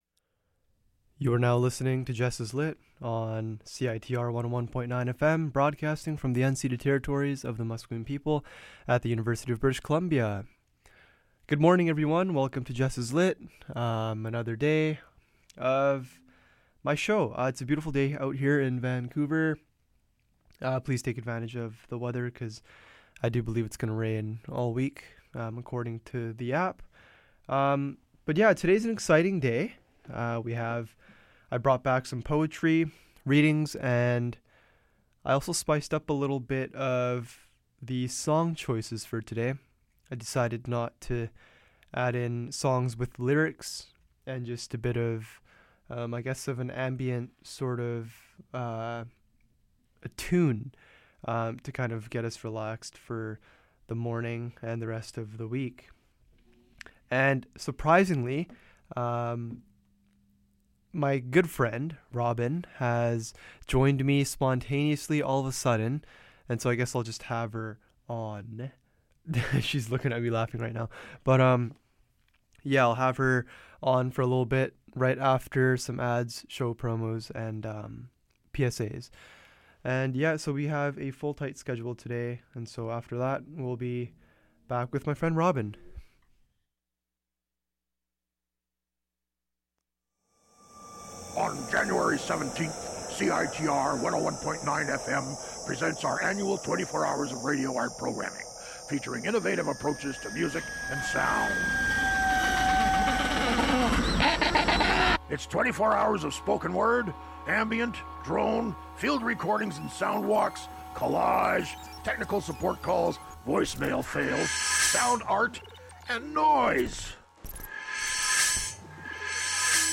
The seocnd half of the show I will read some poems and also play some "new" music in between.